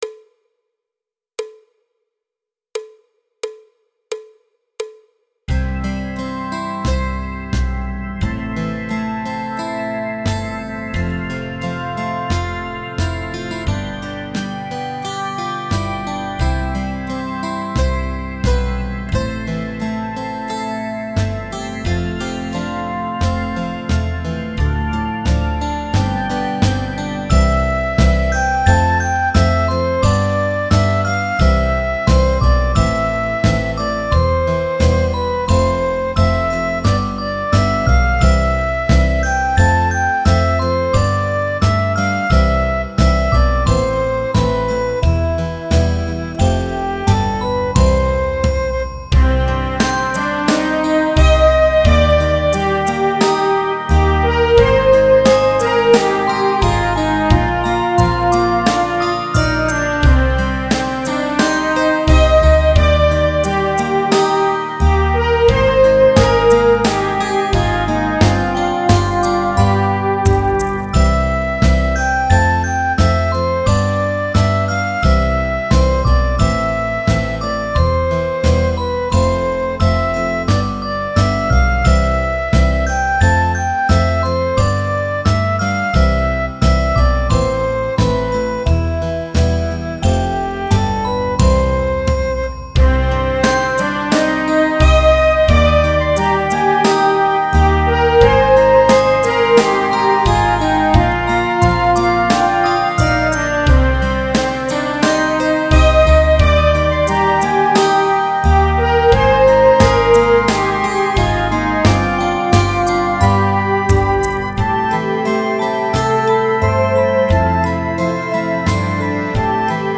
Batterie Solo